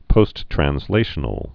(pōsttrăns-lāshə-nəl, -trănz-)